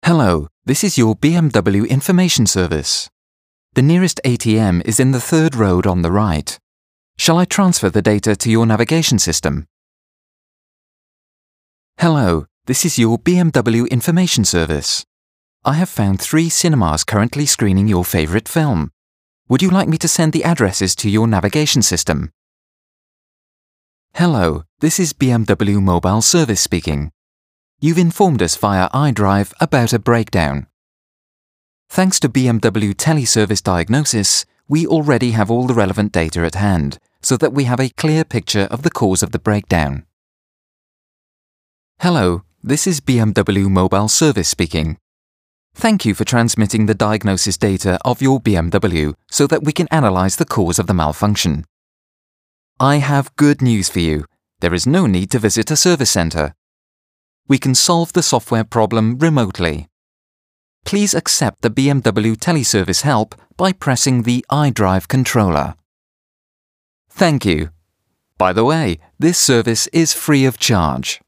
Sprecher englisch.
britisch
Sprechprobe: Werbung (Muttersprache):